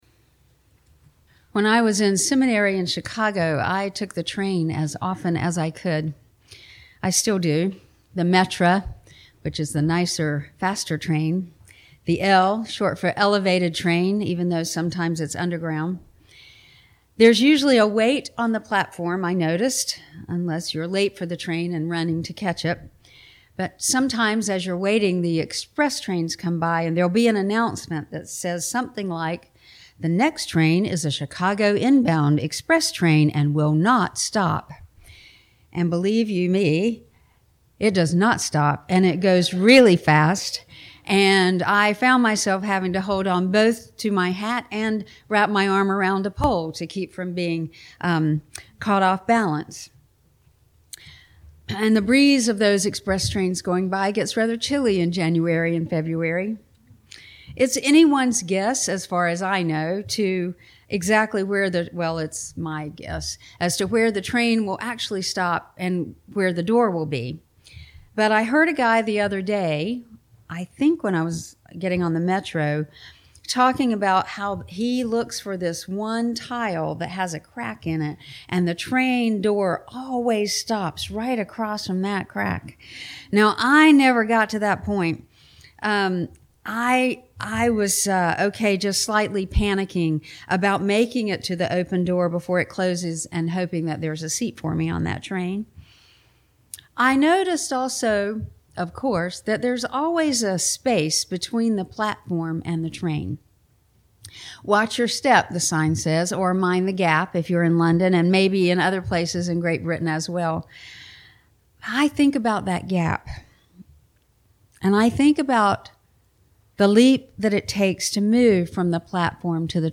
In this reflective sermon, the speaker uses the physical metaphor of boarding a train to describe the existential and spiritual leaps of faith required in human life.